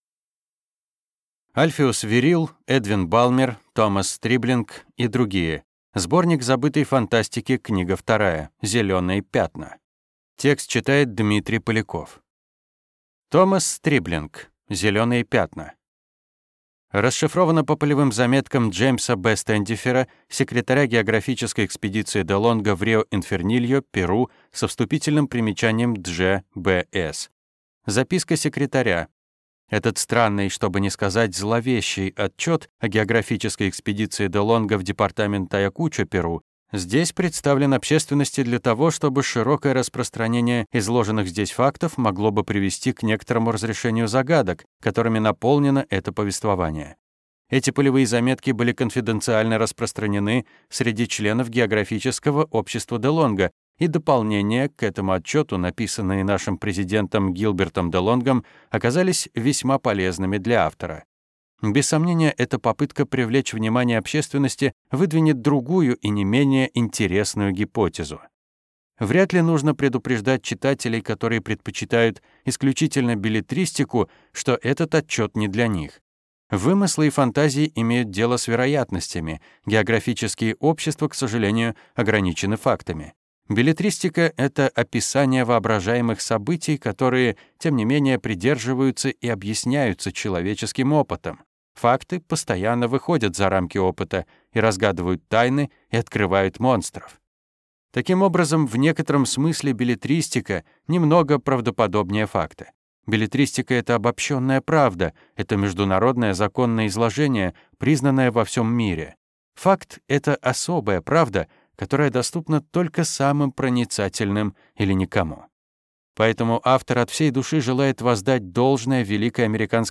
Аудиокнига Сборник забытой фантастики №2. Зеленые пятна | Библиотека аудиокниг